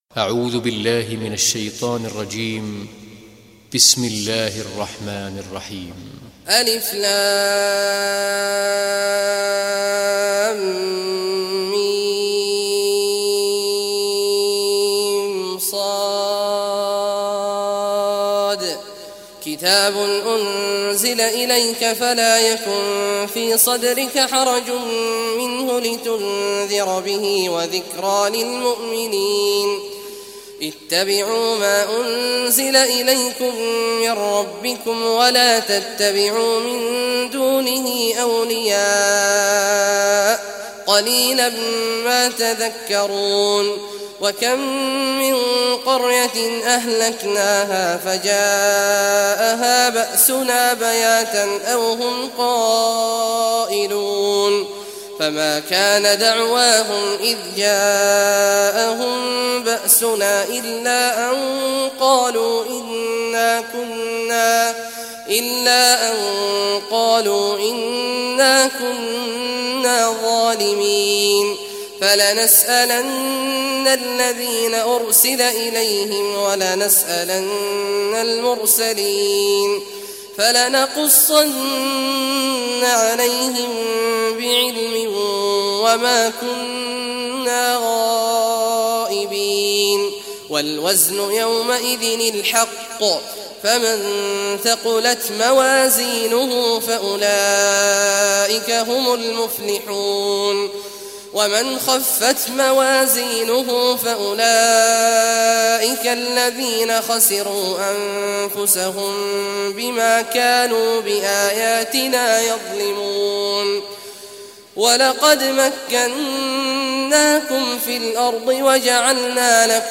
Surah Araf Recitation by Sheikh Awad al Juhany
Surah Araf, listen or play online mp3 tilawat / recitation in Arabic in the beautiful voice of Sheikh Abdullah Awad al Juhany.